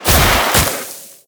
Sfx_creature_penguin_dive_up_to_land_01.ogg